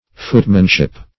Footmanship \Foot"man*ship\, n. Art or skill of a footman.